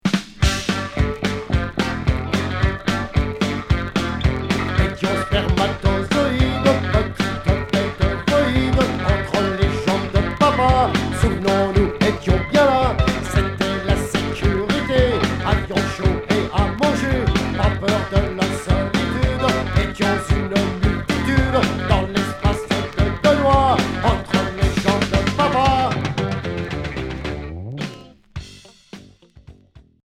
Rock satyrique